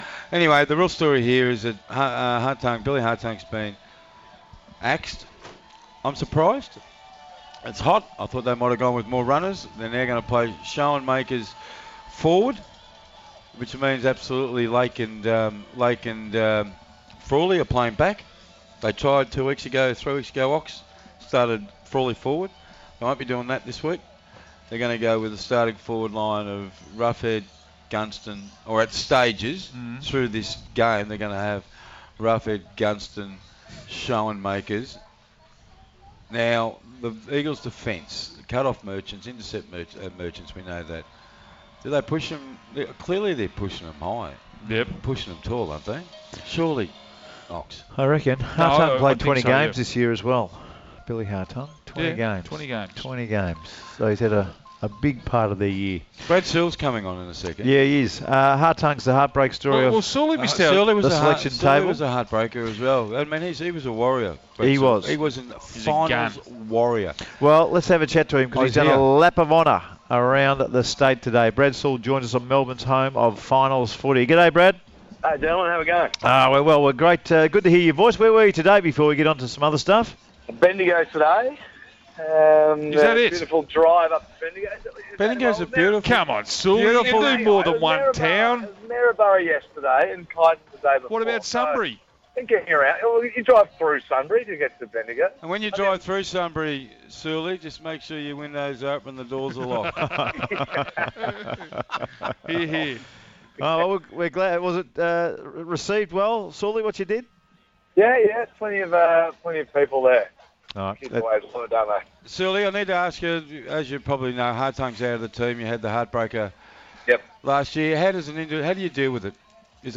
Two-time Premiership player Brad Sewell speaks to The Run Home about the omission of Billy Hartung.